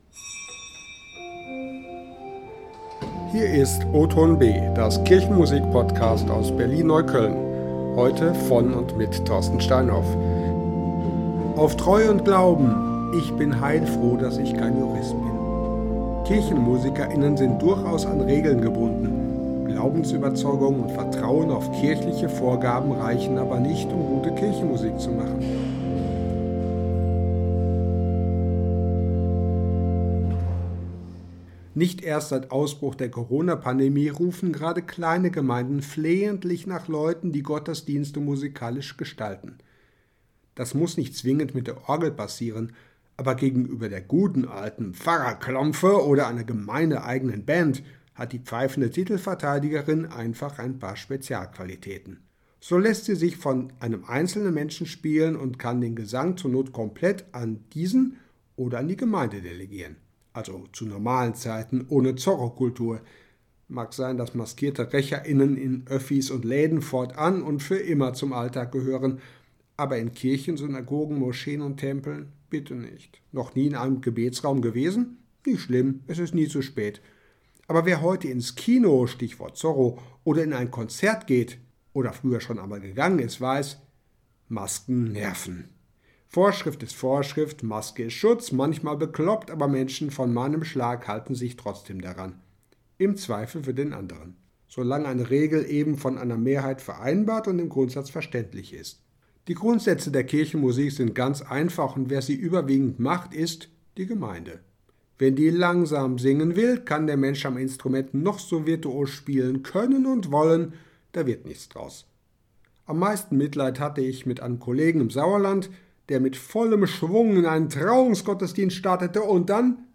• Musik: Vertrau den neuen Wegen (GL Berlin 807), gespielt an der Orgel von St. Christophorus Neukölln